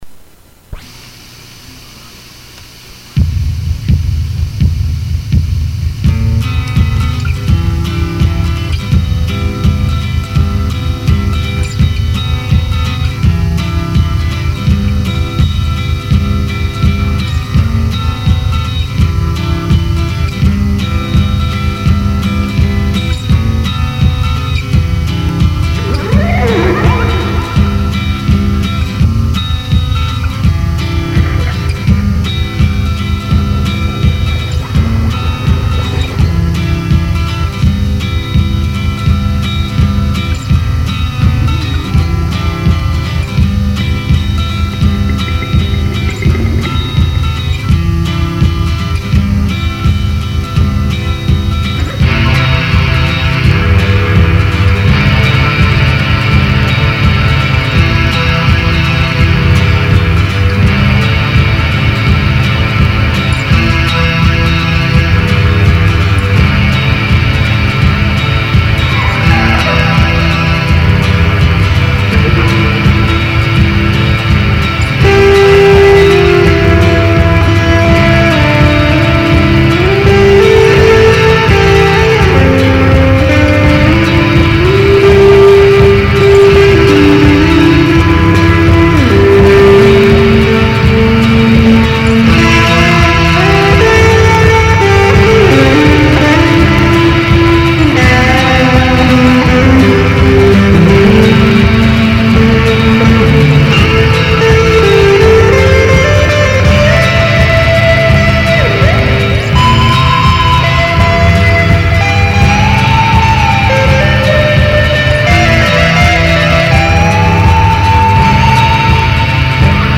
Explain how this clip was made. recorded in my room on a 4-track